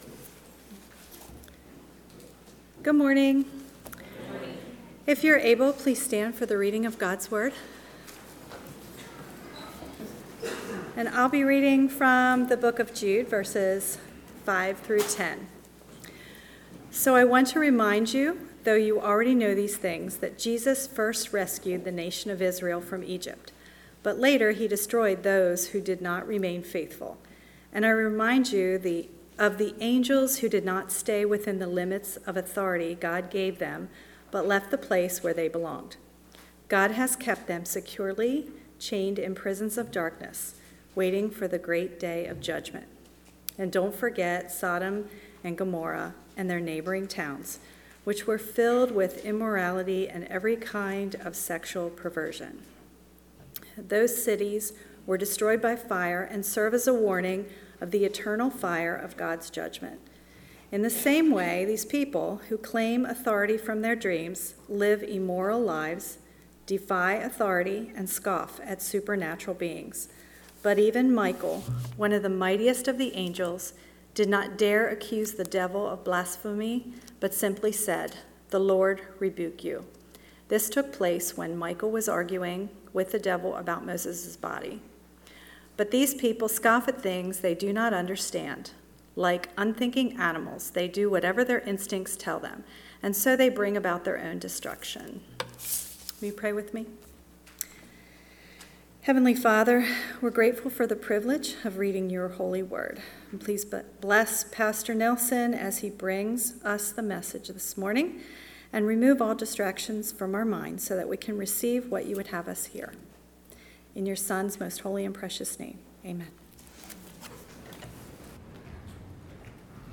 A message from the series "September 2025."